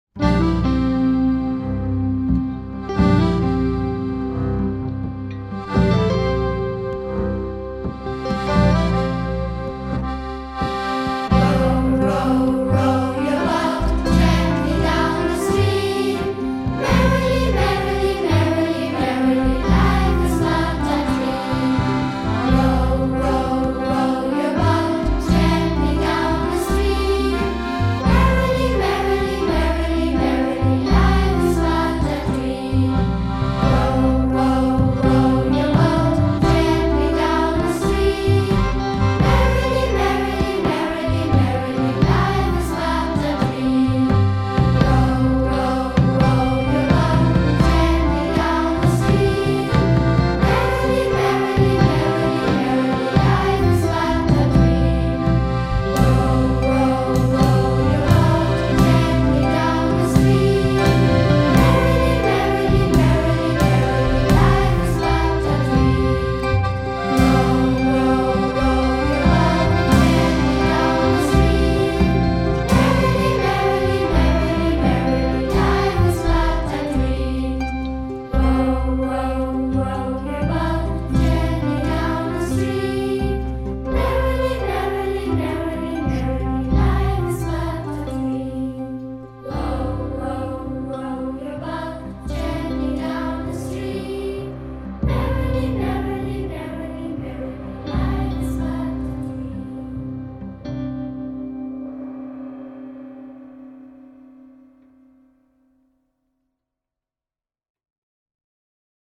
Row your Boat - Einspielung des Liedes